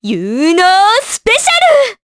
Juno-Vox_Skill3_jp.wav